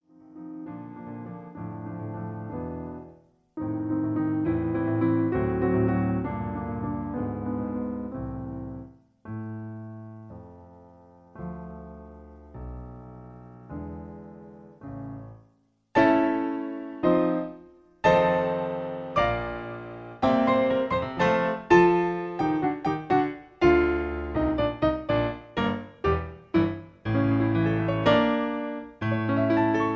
Piano accompaniment track